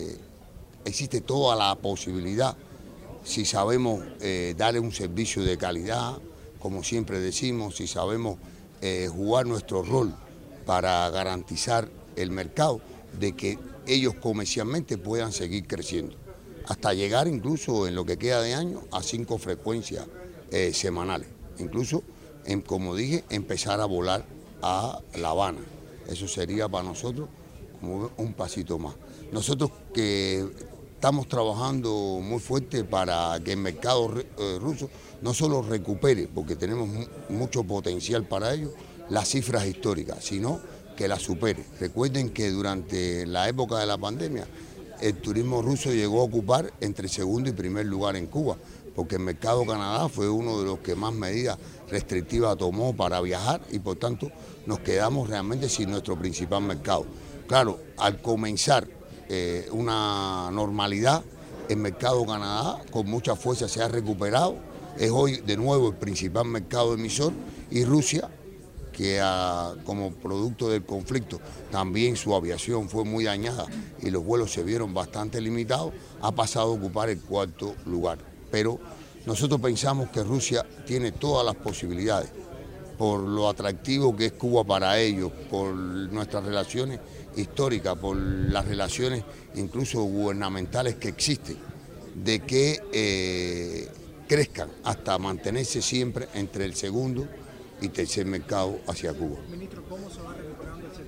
Así lo reconoció el Ministro de Turismo Juan Carlos García Granda durante un intercambio con la prensa matancera en el Aeropuerto Internacional Juan Gualberto Gómez,de Varadero.